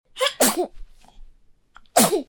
宝宝打喷嚏音效免费音频素材下载